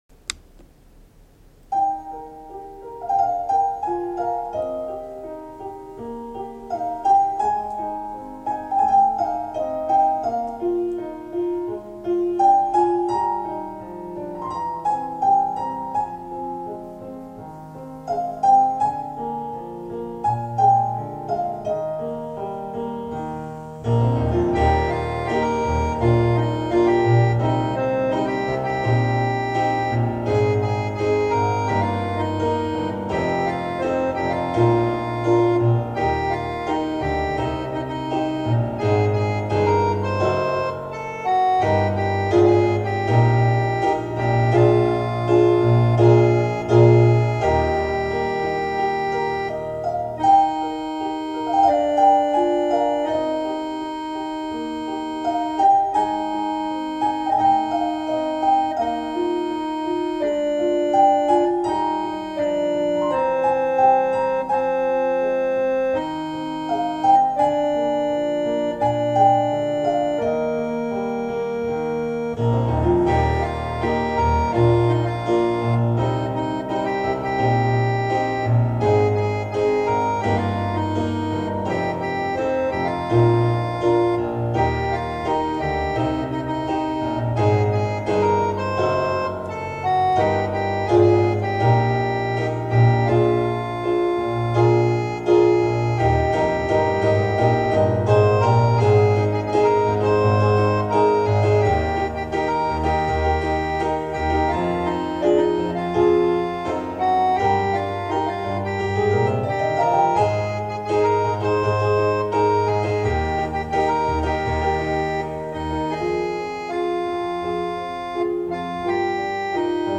ひろい世界へ(ソプラノ).mp3 [6660KB mp3ファイル]